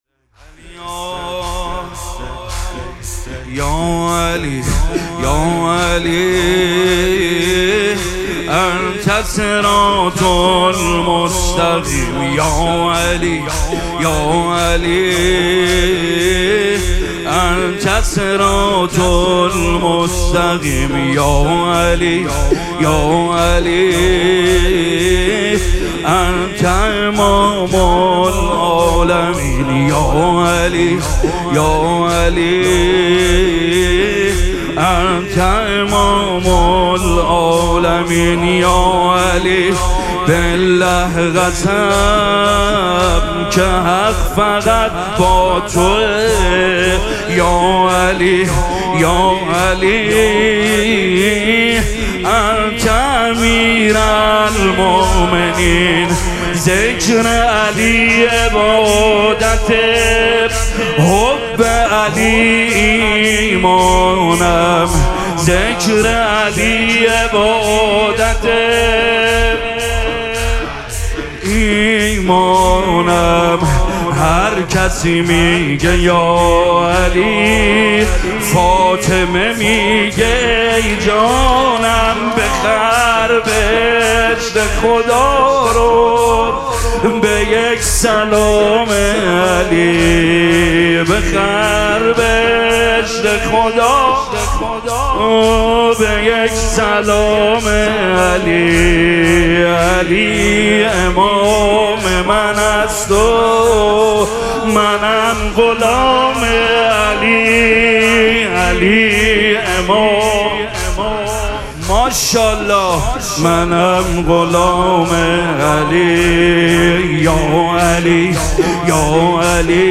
مراسم مناجات شب بیست و دوم ماه مبارک رمضان